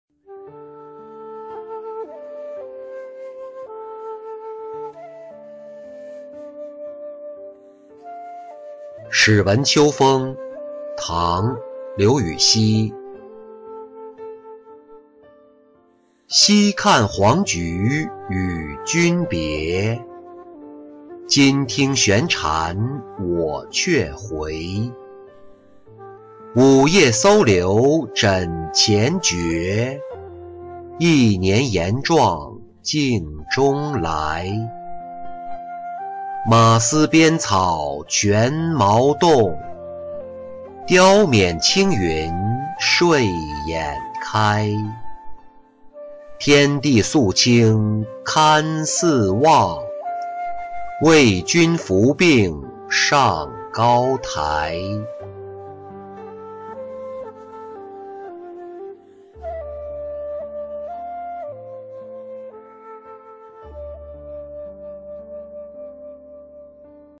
始闻秋风-音频朗读